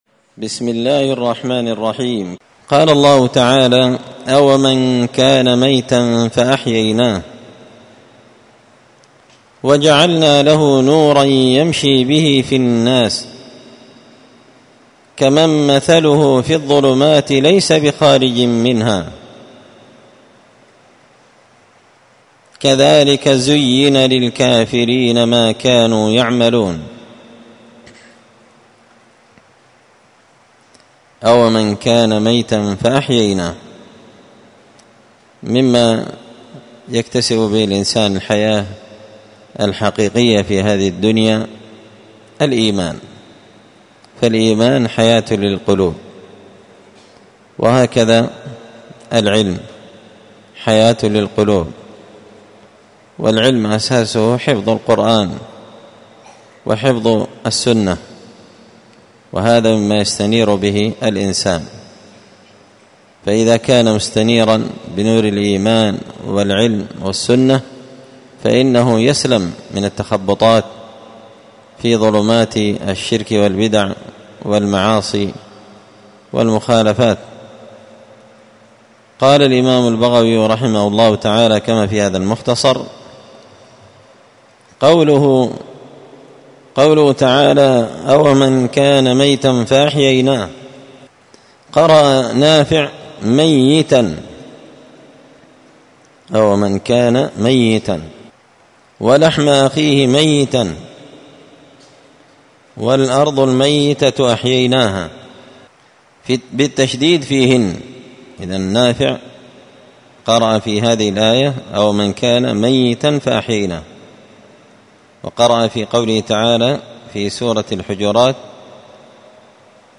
مختصر تفسير الإمام البغوي رحمه الله الدرس 350